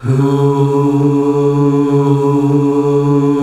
HUUUH   D.wav